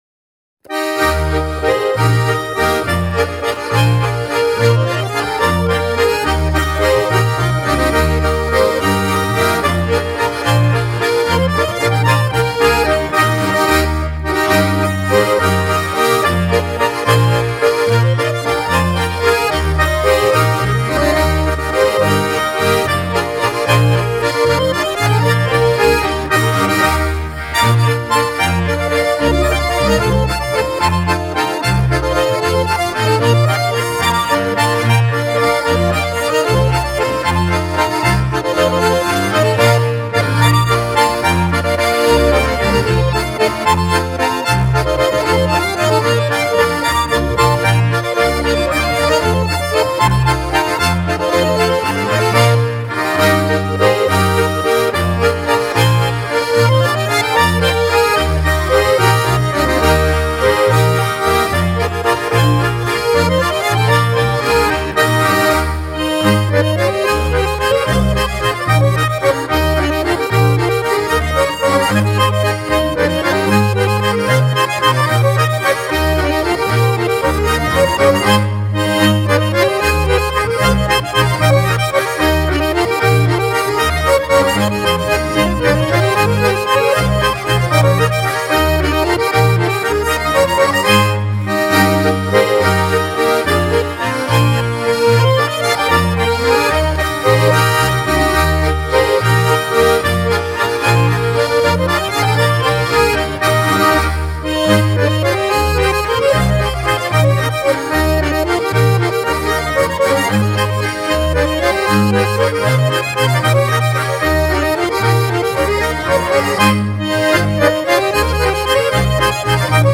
Ländler-Walzer